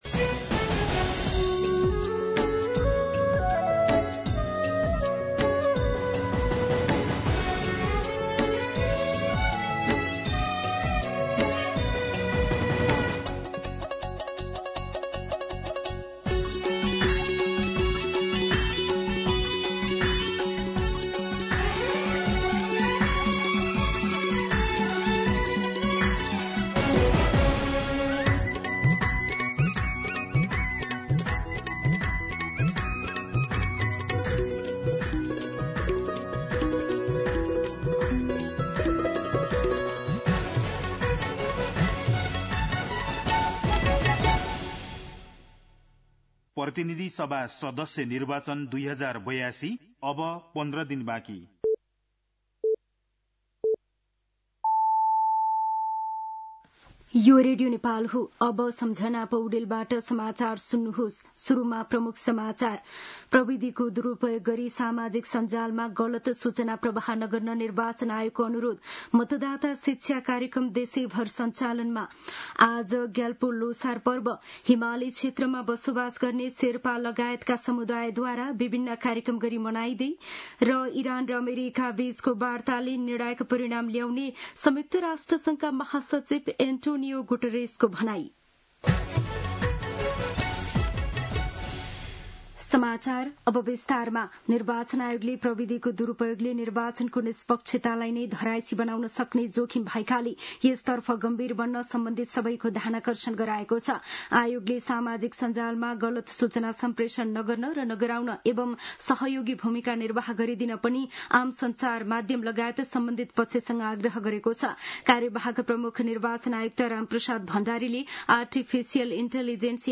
दिउँसो ३ बजेको नेपाली समाचार : ६ फागुन , २०८२
3-pm-Nepali-News-2.mp3